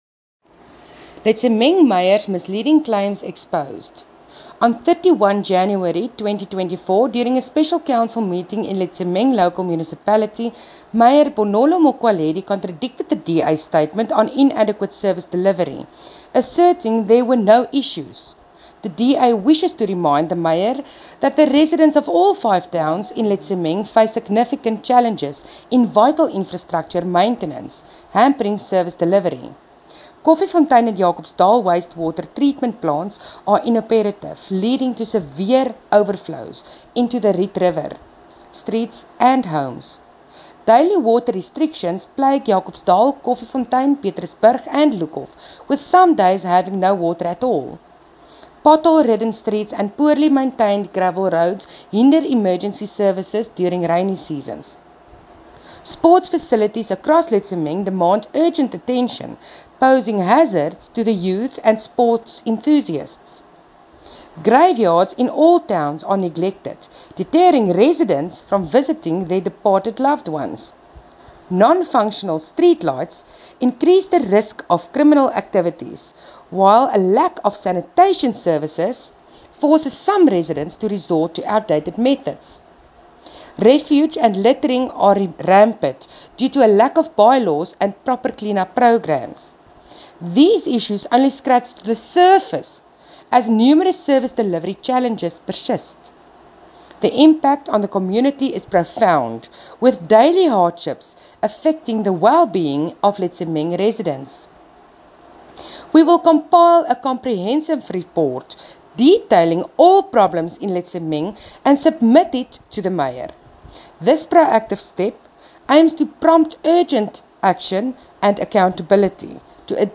Afrikaans soundbites by Cllr Mariska Potgieter and